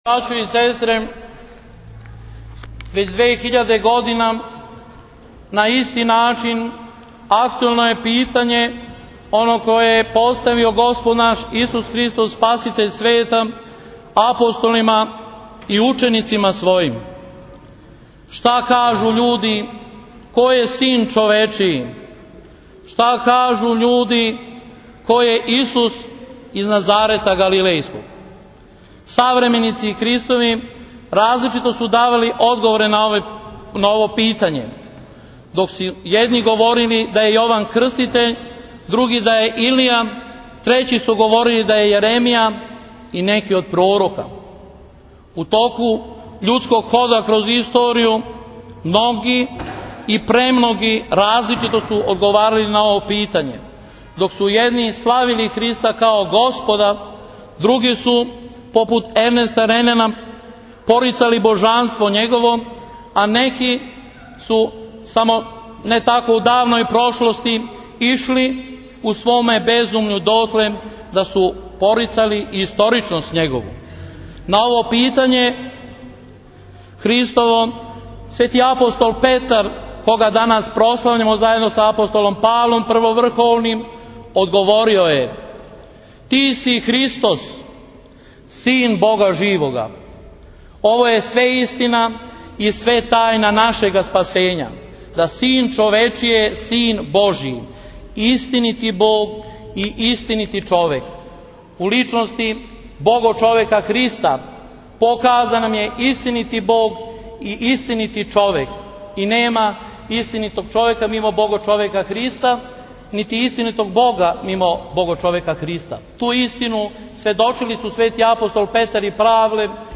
Прослава Петровдана у Србобрану